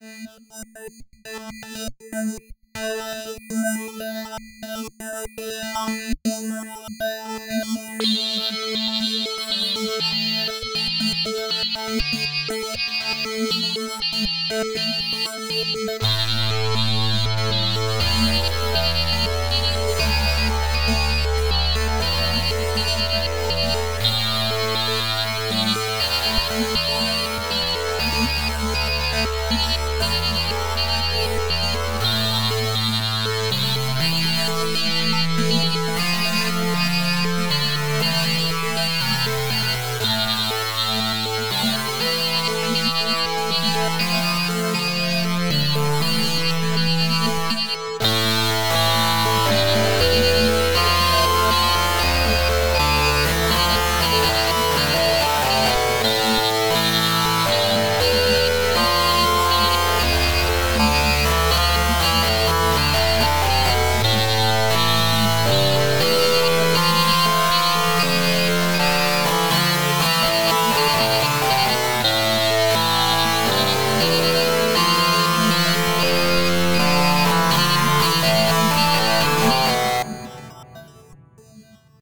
Added a few more ambient tracks.